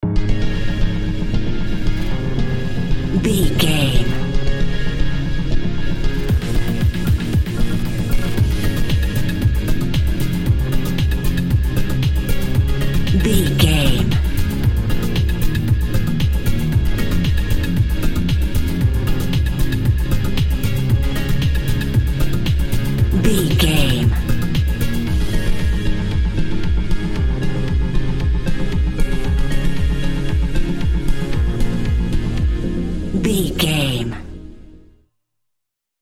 Aeolian/Minor
ethereal
dreamy
cheerful/happy
groovy
synthesiser
drum machine
house
electro dance
synth leads
synth bass
upbeat